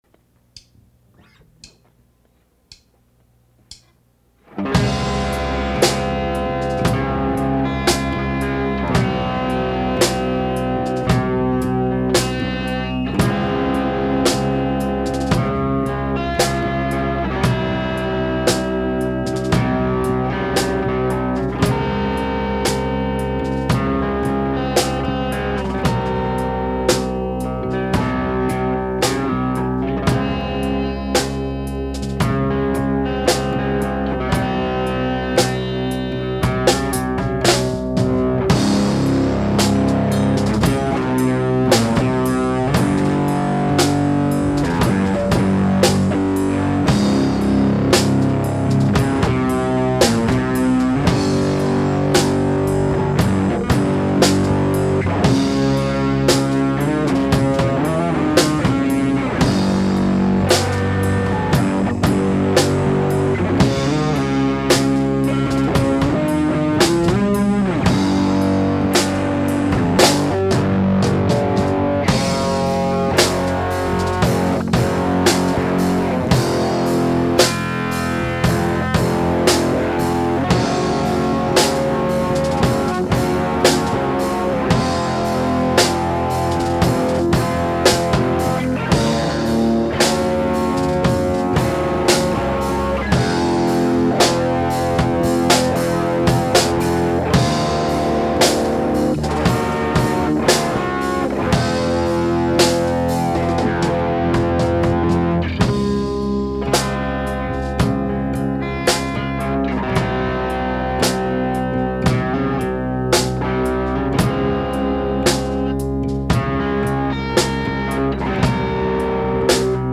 This somewhat languid and moody track was recorded around 1996 by our band Jumbo Skill Crane.
I'm playing guitar, bass
drums
Recorded on a 4-track.